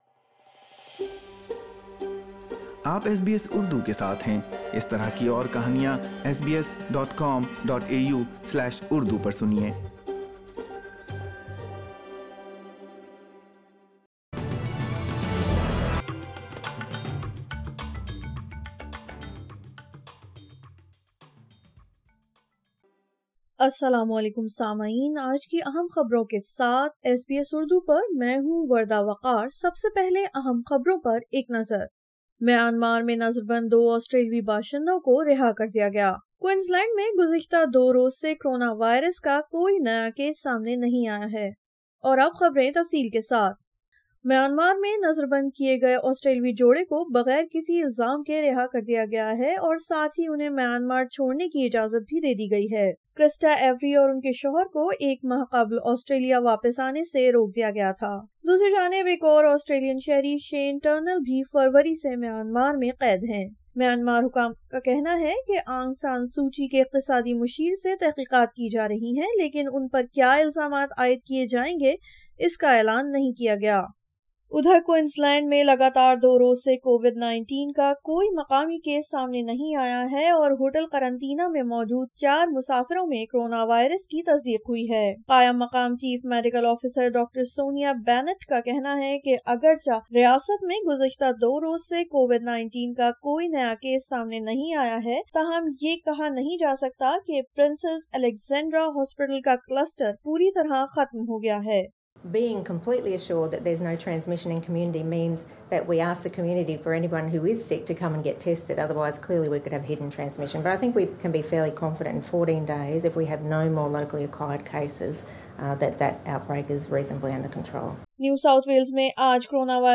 اردو خبریں 05 اپریل 2021